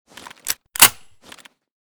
svd_unjam.ogg